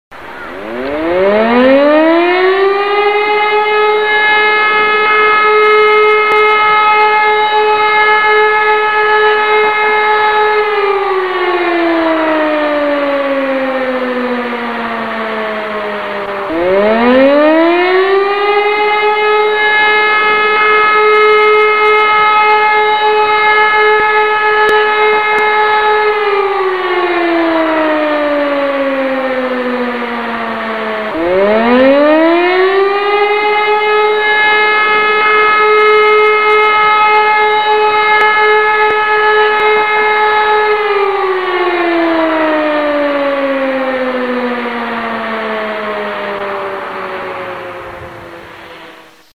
Sirene_Feueralarm_Luftalarm.mp3